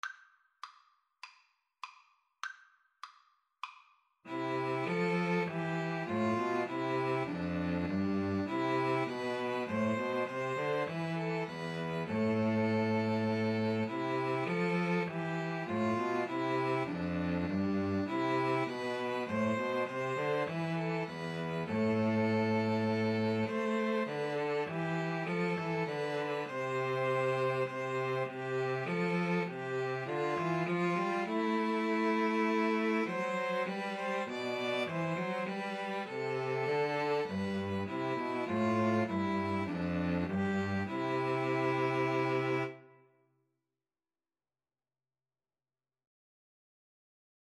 Free Sheet music for String trio
C major (Sounding Pitch) (View more C major Music for String trio )